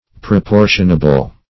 Proportionable \Pro*por"tion*a*ble\, a.